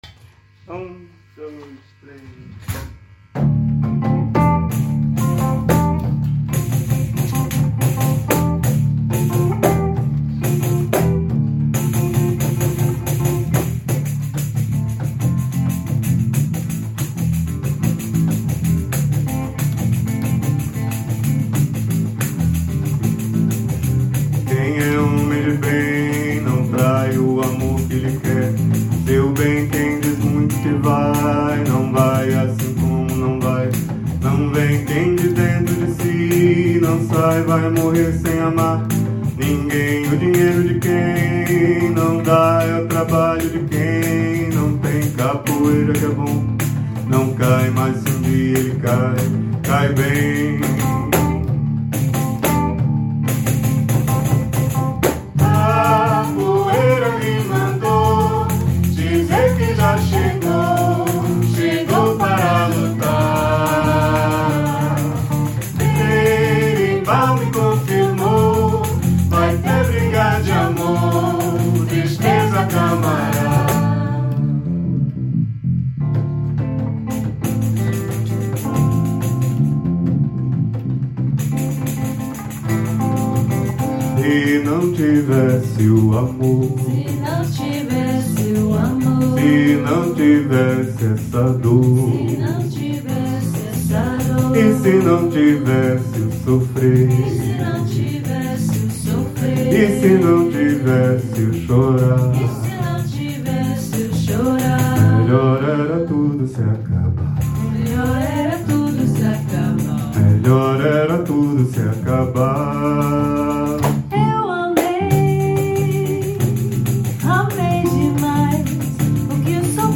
Hier befindet sich eine Auswahl unseres Repertoires mit Sheets und einigen Audio-Aufnahmen der Stücke, die in den Proben gemacht wurden.